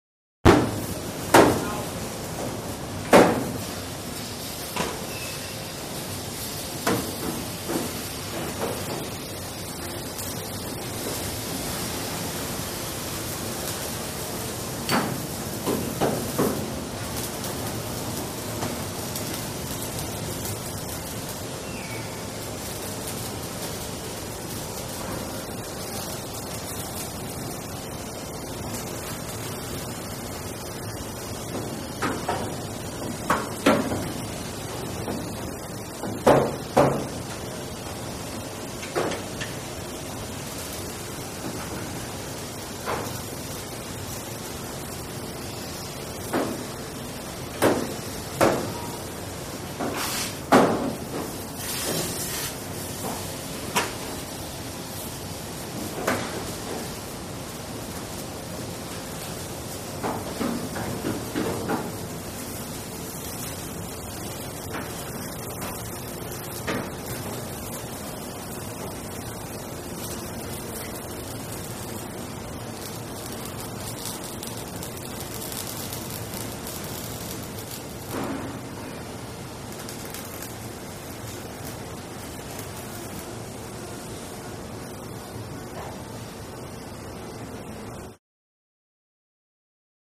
Automobile Factory | Sneak On The Lot
Automobile Factory Background; Loud Metallic Clunks, Talking, Welding, Steady Air Release; Medium Perspective.